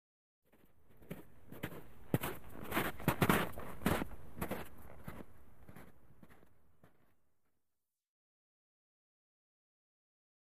FootstepsSnowBy WES094801
Snow Hiking; Snow Walk By With Boots.